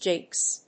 音節jinks 発音記号・読み方
/dʒíŋks(米国英語)/